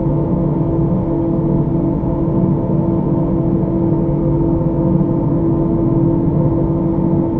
largefanRev_2.WAV